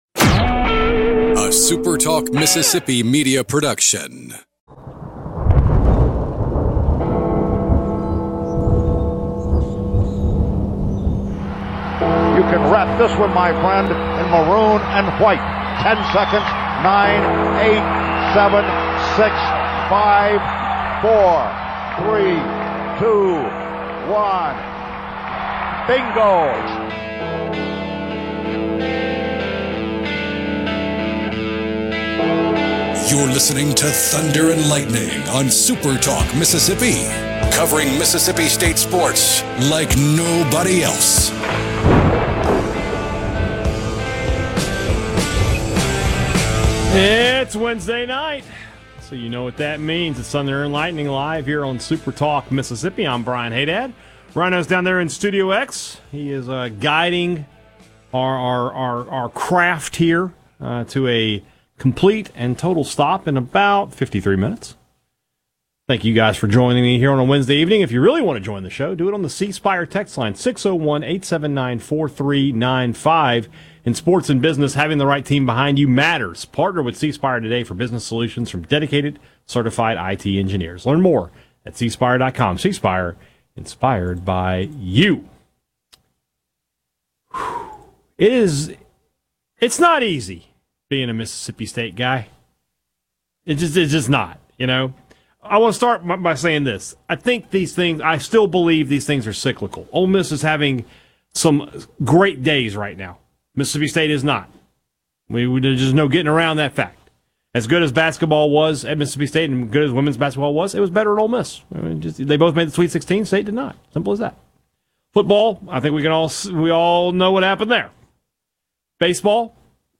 SuperTalk Media Thunder & Lightning Live 2025-04-09